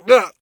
pain_11.ogg